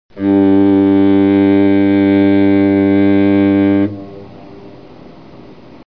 Foghorn sound 3